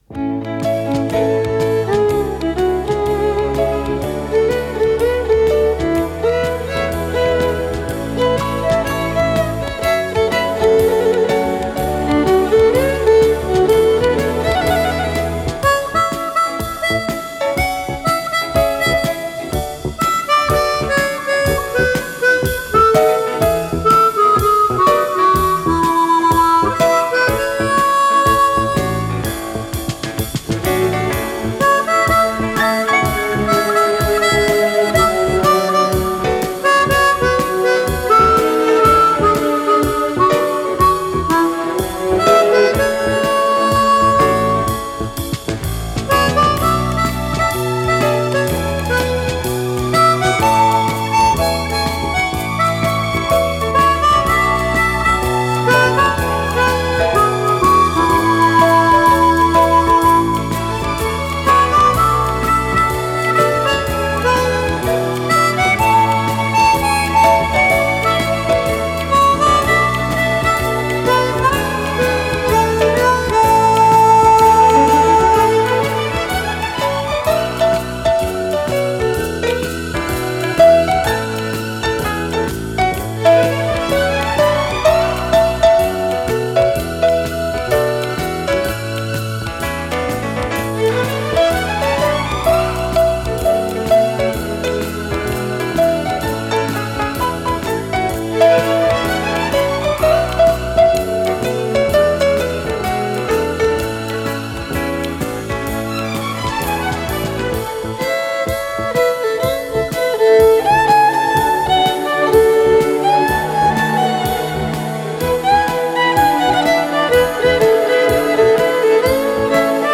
с профессиональной магнитной ленты
Соло на губной гармошке
ВариантДубль моно